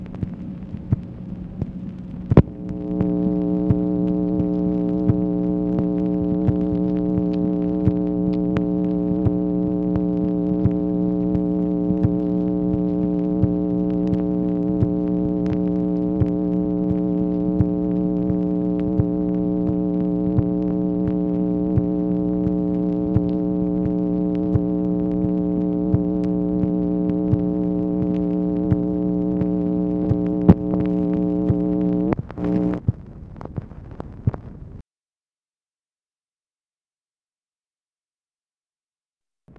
MACHINE NOISE, August 30, 1965
Secret White House Tapes | Lyndon B. Johnson Presidency